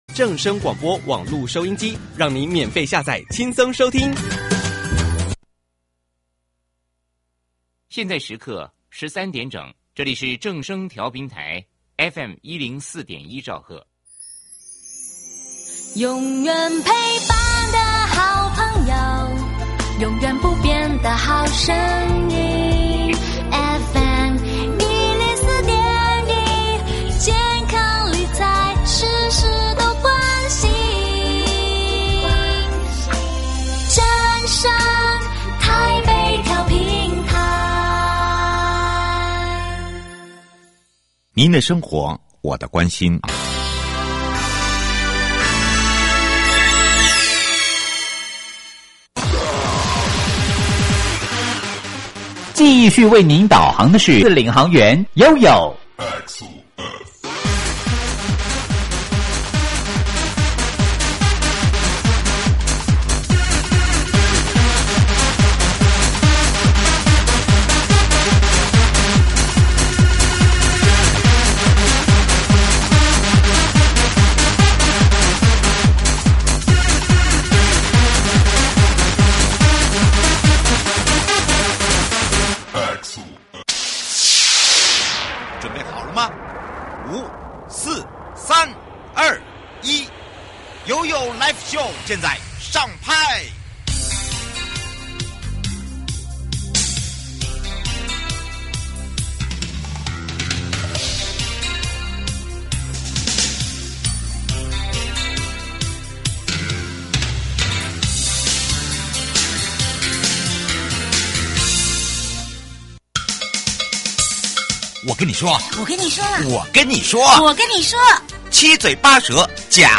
接下來，我們就用一連串問答的方式，一起來揭開「人本景觀設計」的奧秘！